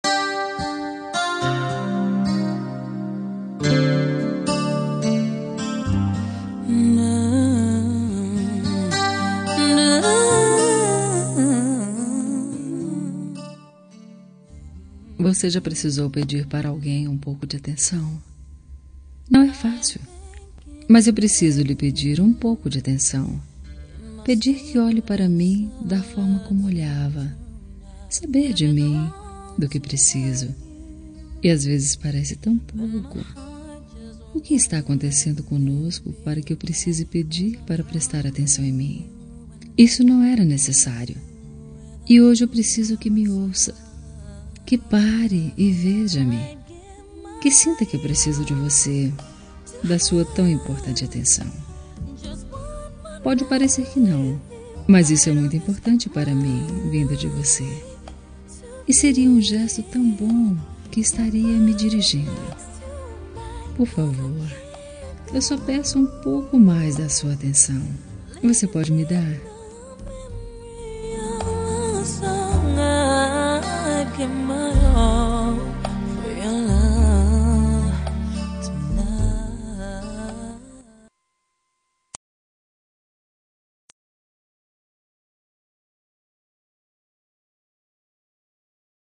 Toque para Não Terminar – Voz Feminina – Cód: 463
463-me-de-atencao-fem.m4a